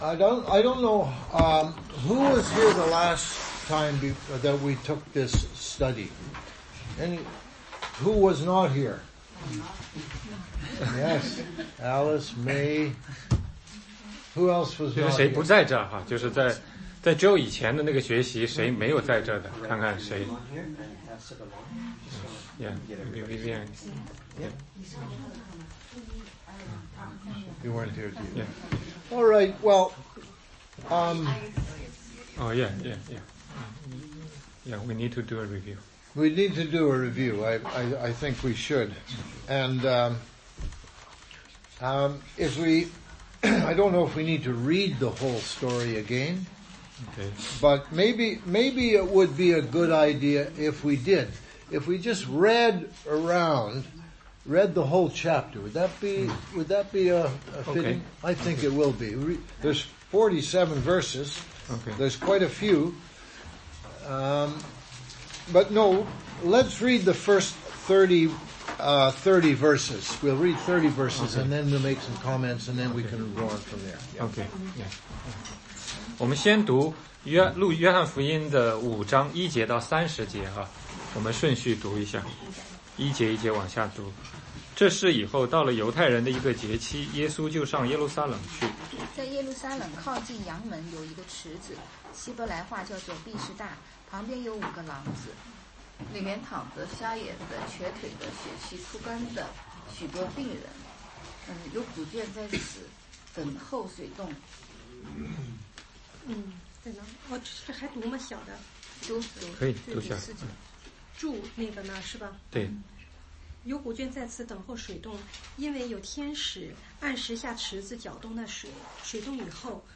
16街讲道录音 - 约翰福音5章复习